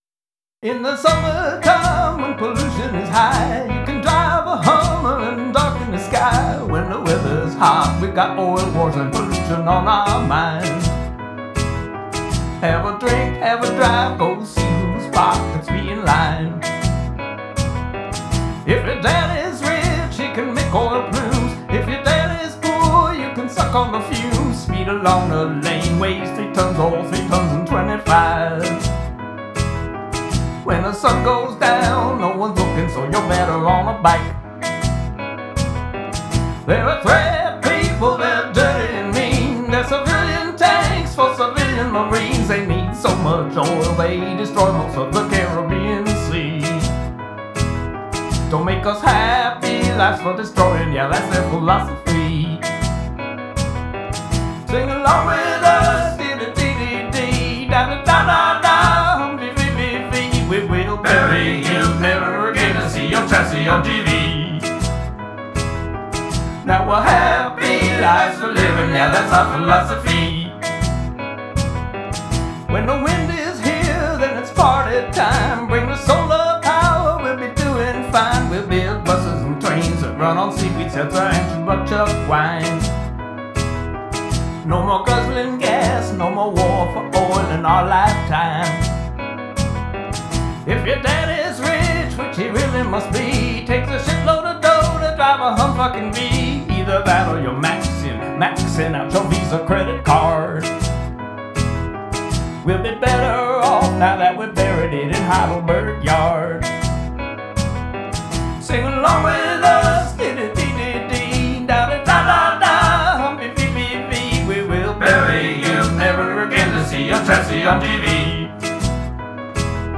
world’s most unsingable singalong.